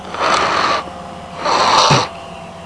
Blowing Nose (nasty) Sound Effect
Download a high-quality blowing nose (nasty) sound effect.
blowing-nose-nasty.mp3